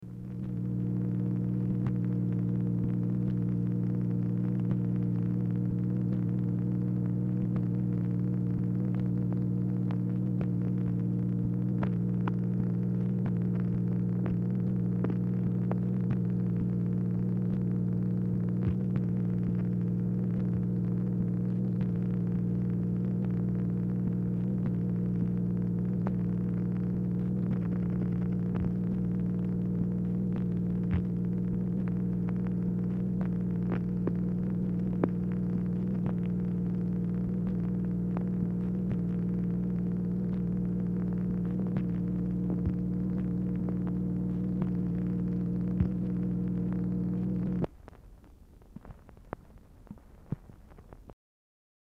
Telephone conversation # 484, sound recording, MACHINE NOISE, 12/13/1963, time unknown | Discover LBJ
Format Dictation belt
Specific Item Type Telephone conversation